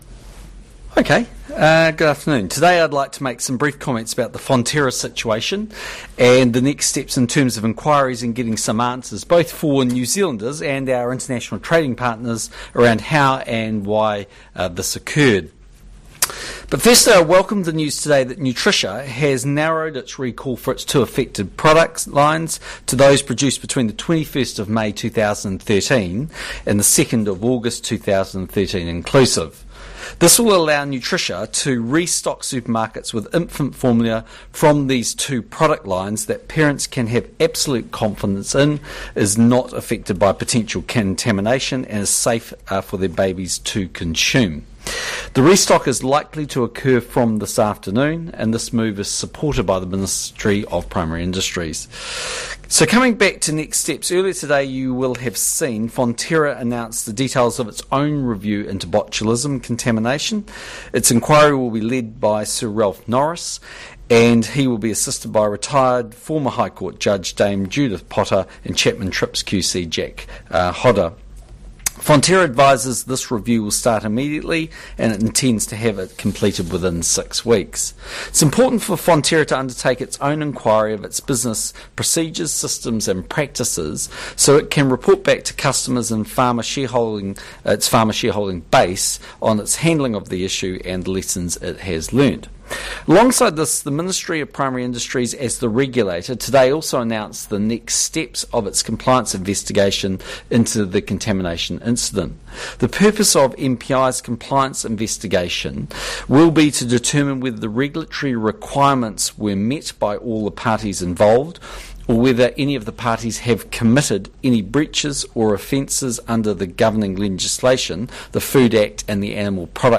PM Press Conference – ACC Levies Fall | Chorus | Snowden | Scoop News
At his weekly post cabinet press conference Prime Minister John Key announced ACC levy reductions for worker and employers, discussed the independent report on Chorus copper pricing due on Thursday, and said the GCSB did not collect wholesale metadata ...